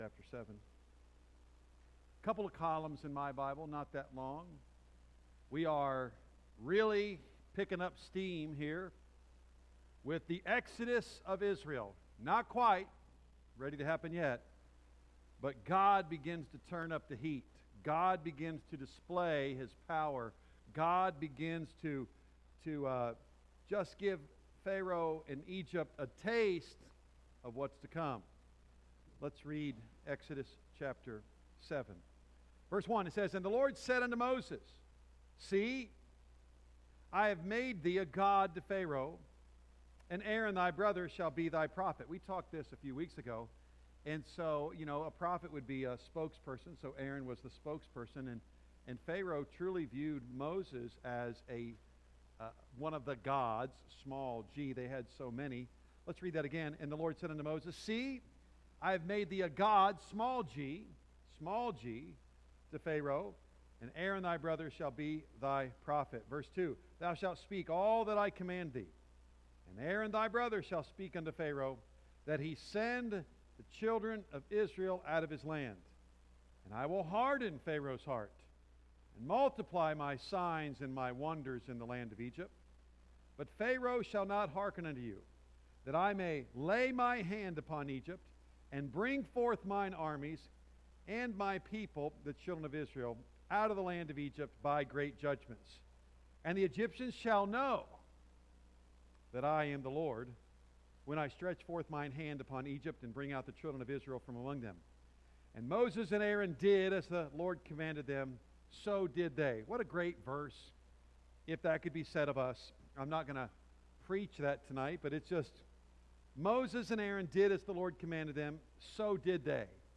A message from the series "Exodus."